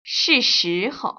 [shì shí‧hou] 스스호우  ▶